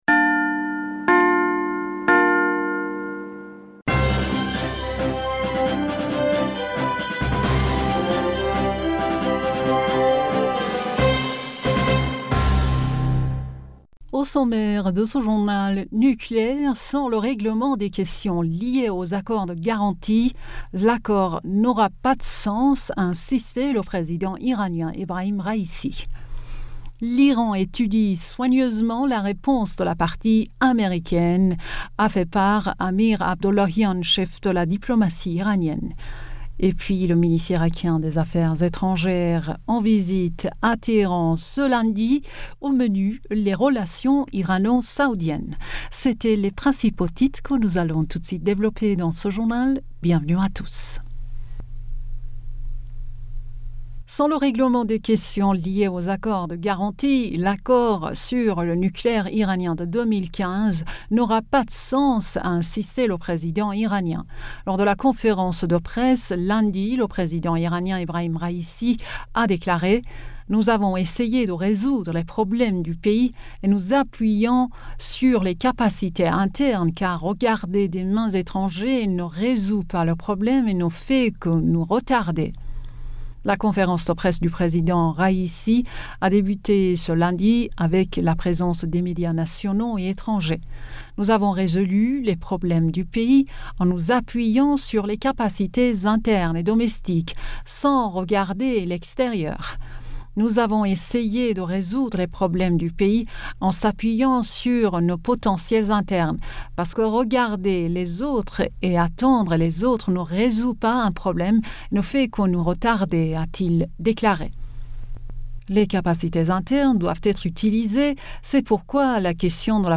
Bulletin d'information Du 29 Aoùt